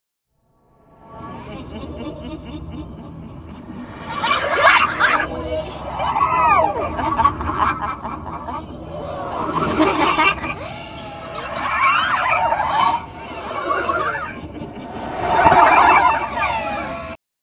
Голоса с того света
--golosa-s-togo-sveta.mp3